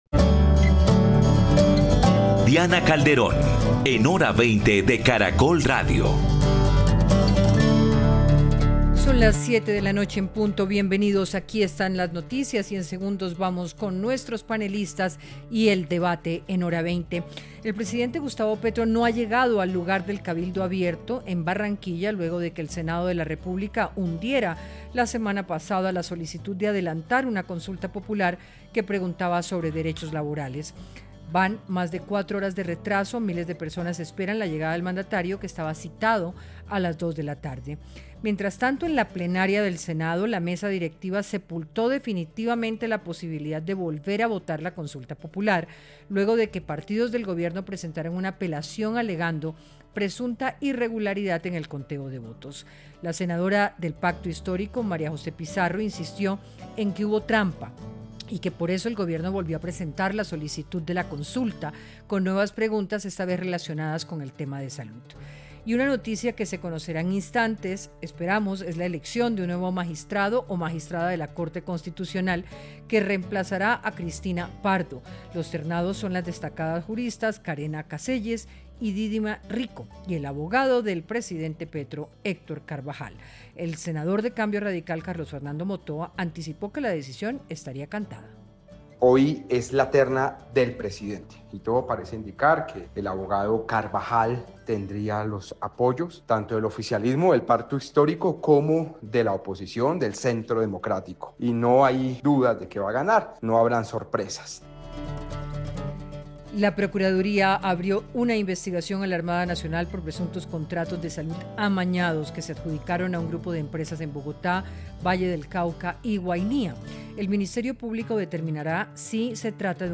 Panelistas plantearon lo que implica el discurso del presidente en Barranquilla, las demoras en la elección de magistrado a la Corte Constitucional y el escenario post-hundimiento de la Consulta Popular.